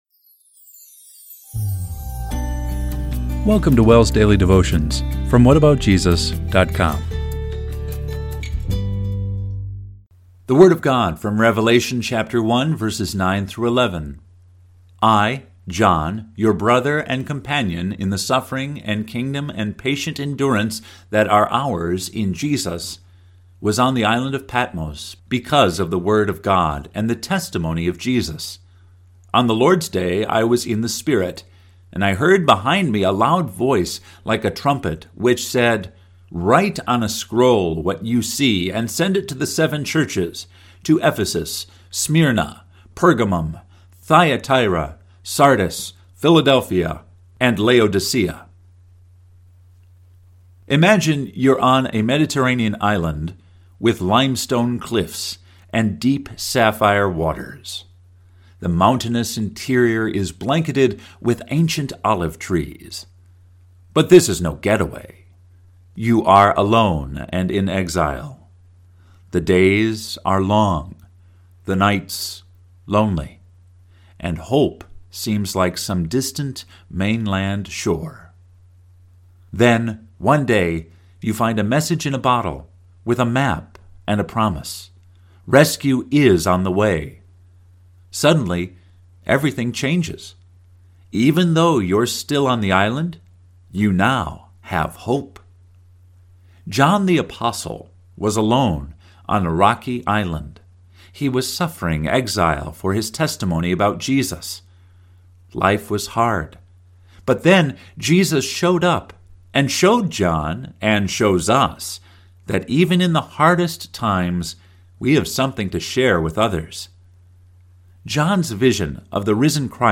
Devotion based on Revelation 1:9-11